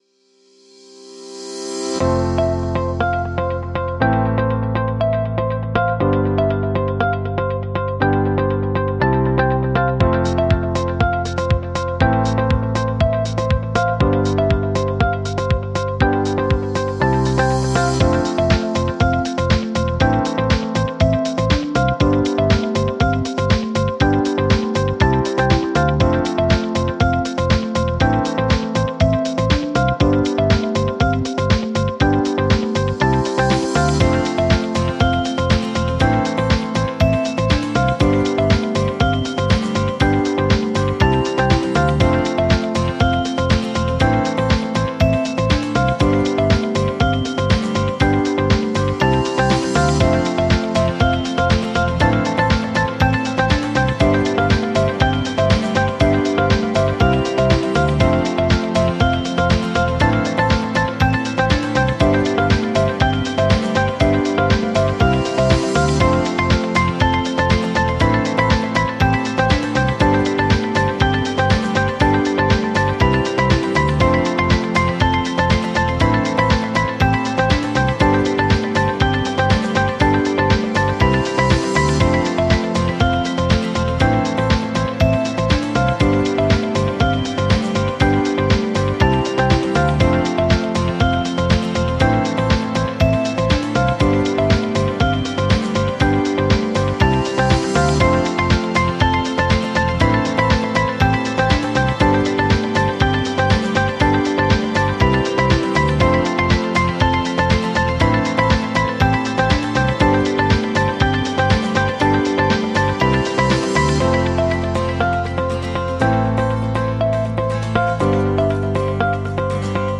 Фоновая веселая музыка для презентаций без слов